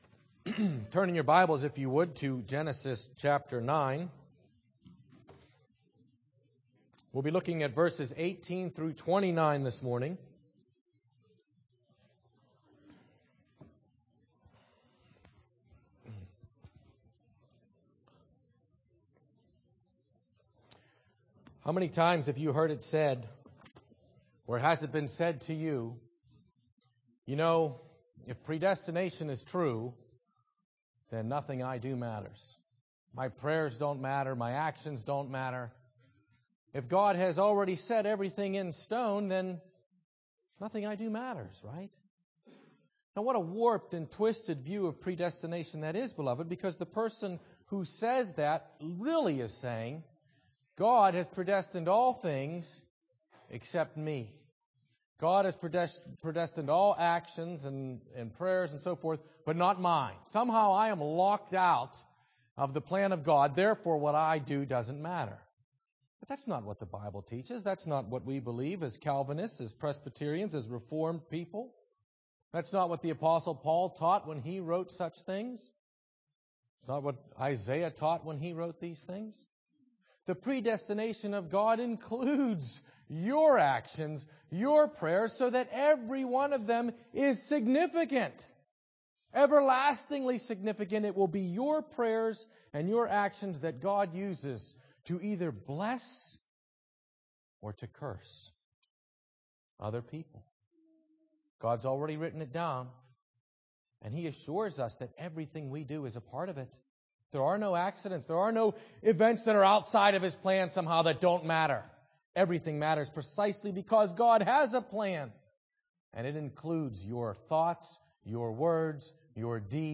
00:00 Download Copy link Sermon Text Genesis 9:18–29